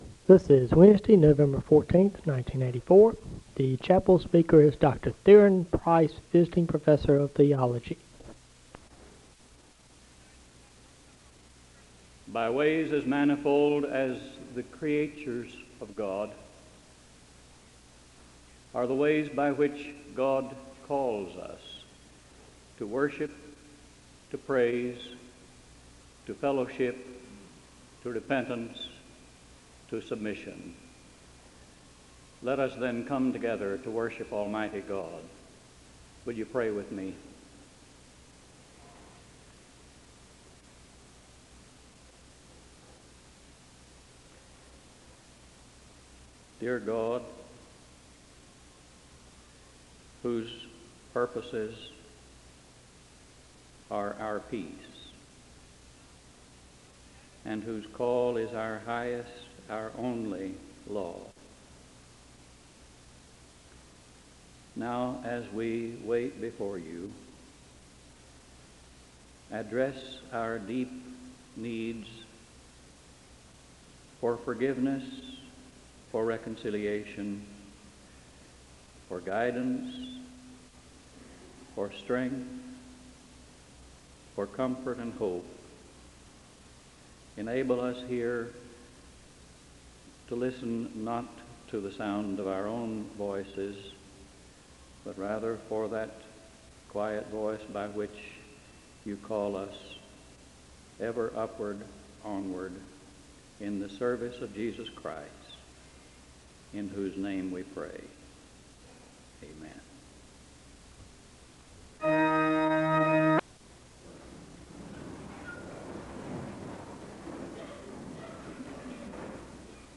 The service begins with a word of prayer (00:00-01:54). The speaker delivers the Scripture reading from Psalm 145 (01:55-04:20).
The choir sings the anthem (05:18-06:38).